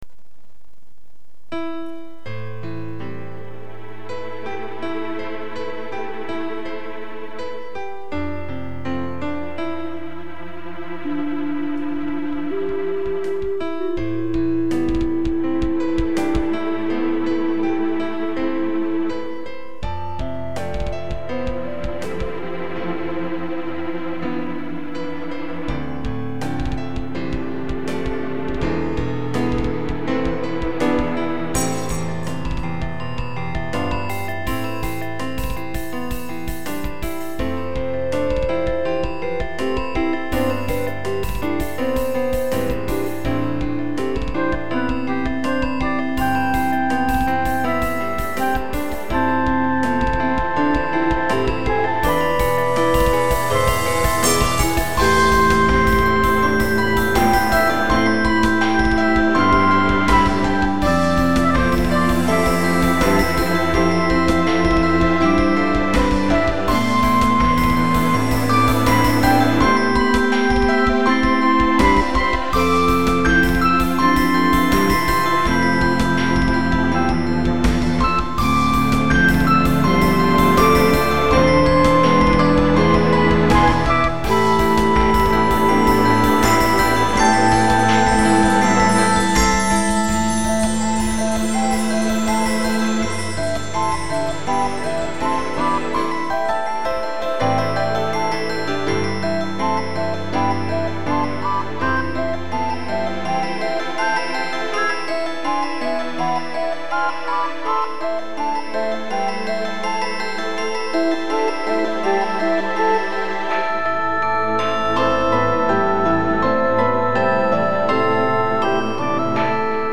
ドラムパートは賛否両論。